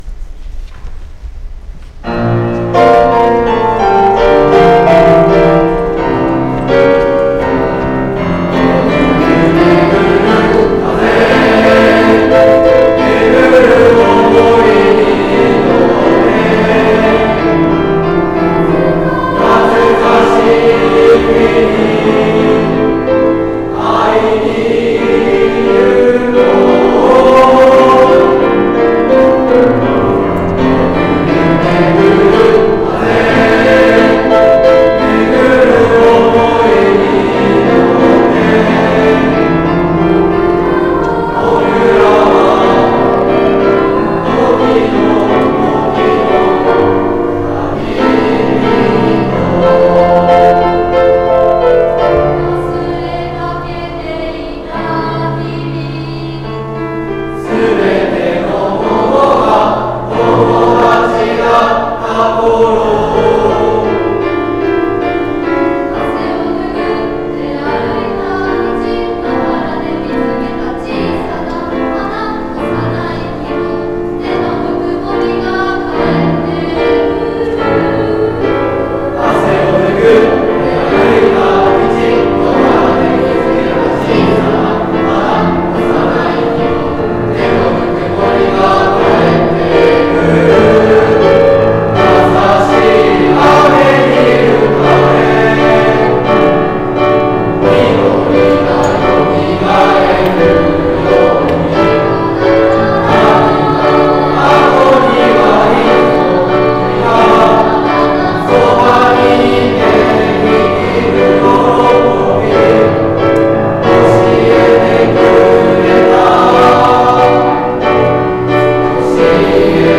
３年生合唱コンクール
先日行われた合唱コンクールが下の曲名をクリックすると聴くことができます。